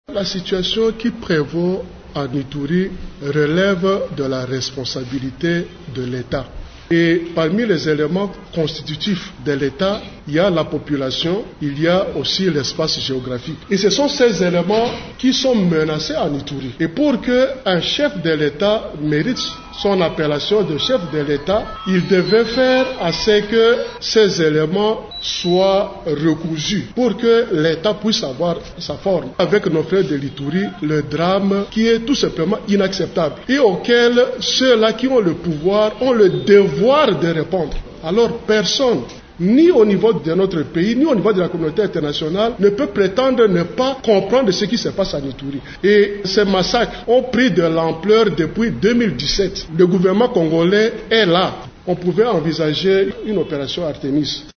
Pour désamorcer la crise, il propose au gouvernement congolais d’envisager avec l’appui de la communauté internationale, l’intervention d’une force du genre « Artémis », intervenue en 2003 en Ituri. Thomas Lubanga l’a dit lors d’une conférence de presse mardi 10 avril à Kinshasa.